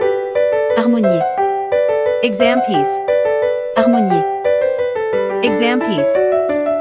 • 人声数拍
我们是钢琴练习教材专家